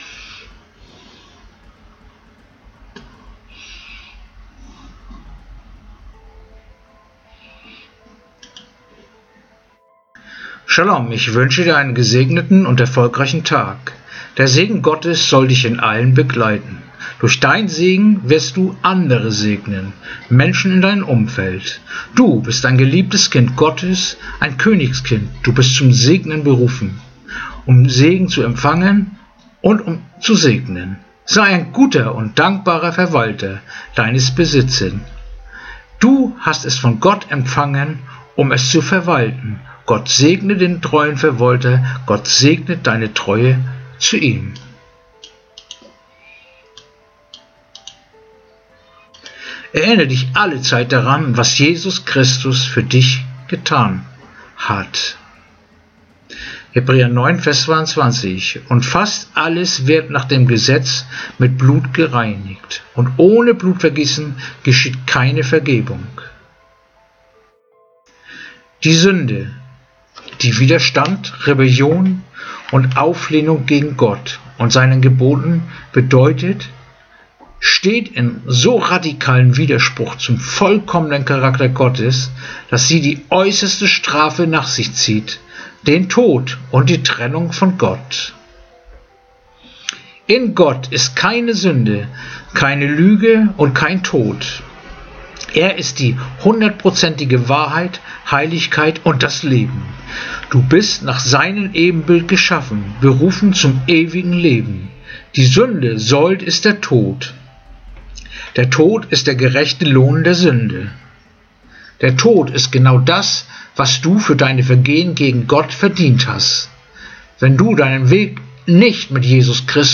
Andacht-vom-24-April-Hebräer-9-22